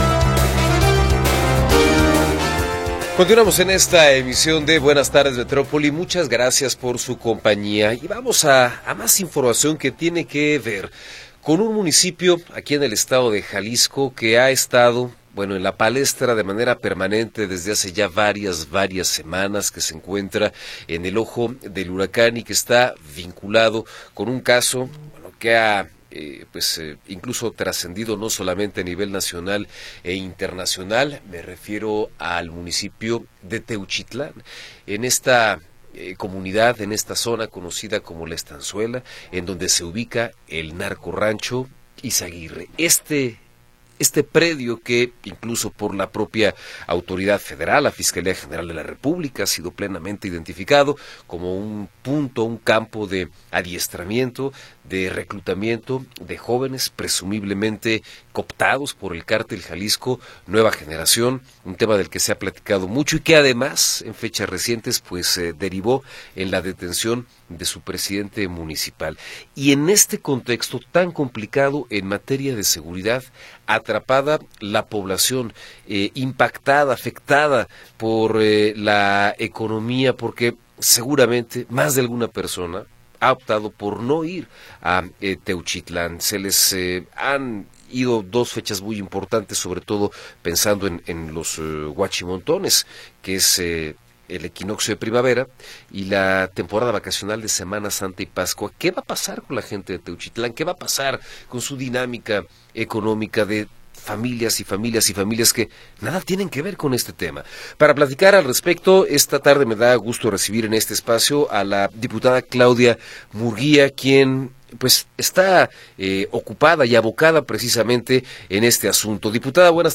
Entrevista con Claudia Murguía Torres
Claudia Murguía Torres, coordinadora de la fracción del PAN en el Congreso de Jalisco, nos habla sobre la propuesta de estrategia para reactivar la economía en Teuchitlán.